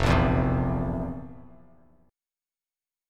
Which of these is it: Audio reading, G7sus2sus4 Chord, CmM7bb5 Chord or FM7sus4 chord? FM7sus4 chord